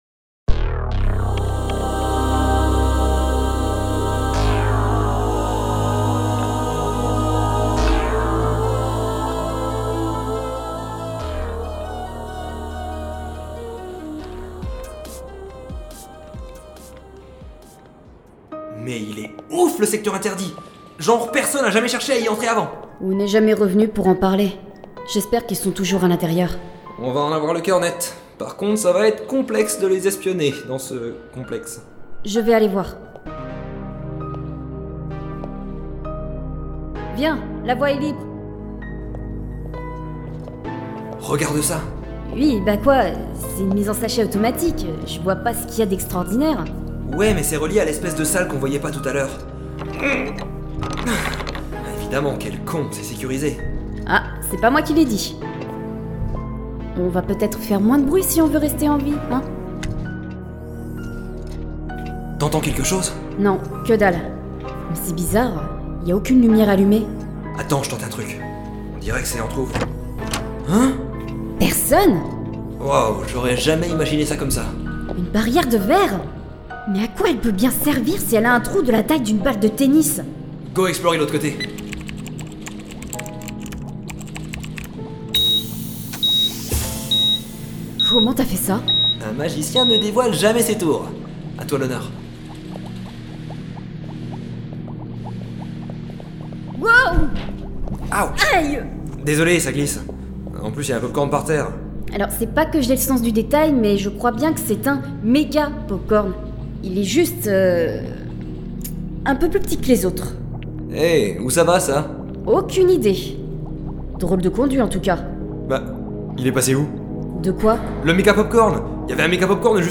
Retrouvez dans ce CD un mix Science Fiction / Fantasy grâce à nos deux fictions audio courtes : Popcorns et ANKH.